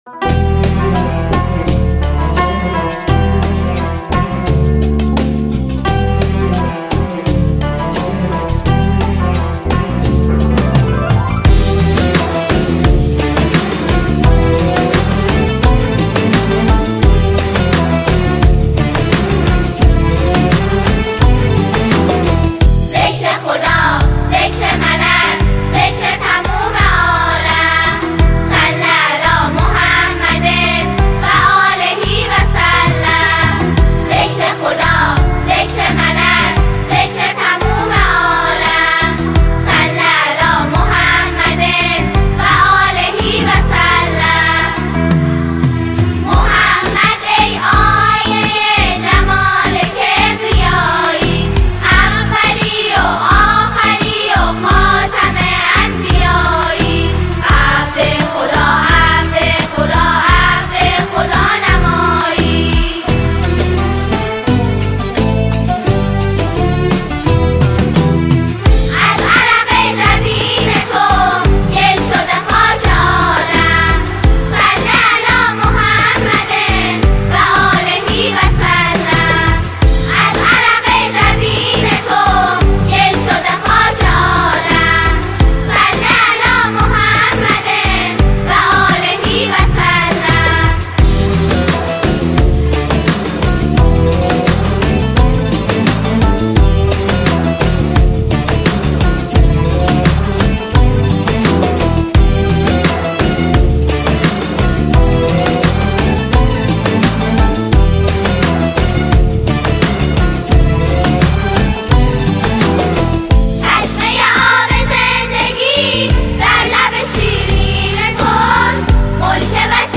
اثری دلنشین و شوق‌انگیز
با صدای لطیف و پرشور دختران نوجوان
ژانر: سرود